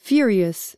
furious /100/ /’fjʊər.i.əs/ /’fjʊr.i.əs/